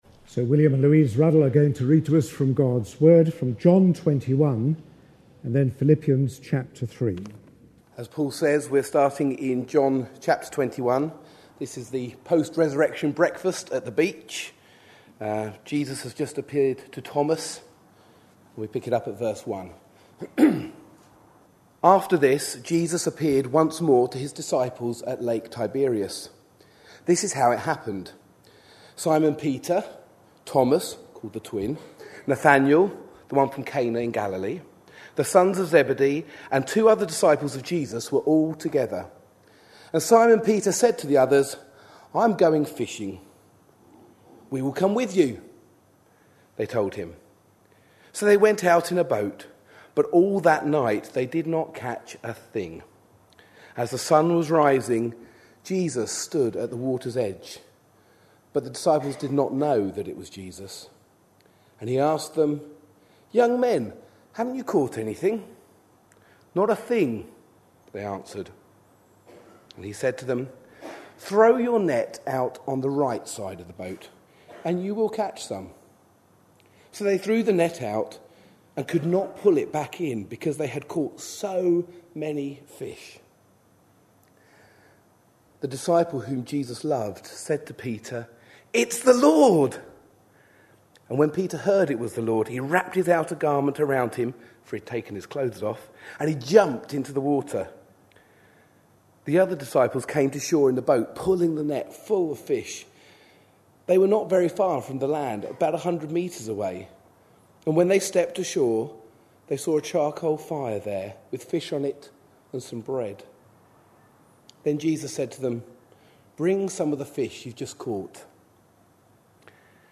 A sermon preached on 6th June, 2010.